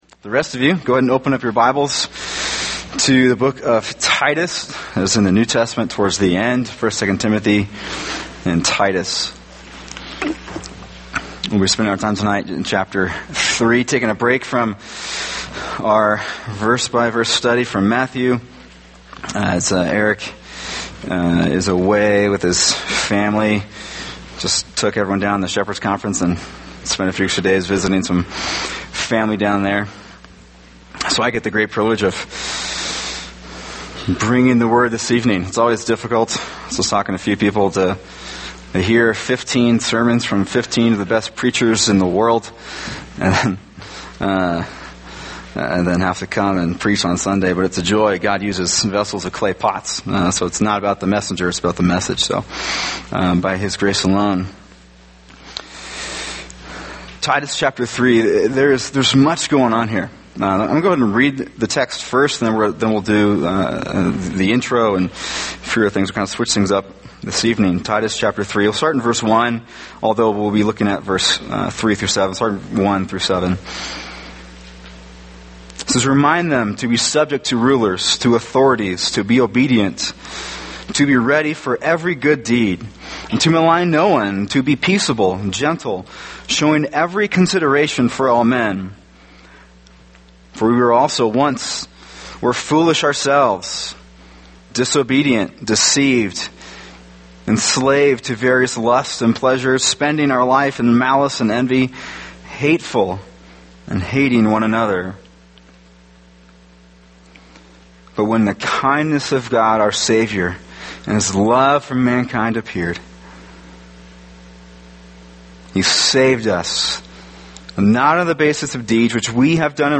[sermon] Titus 3:3-7 The Only Hope for the World | Cornerstone Church - Jackson Hole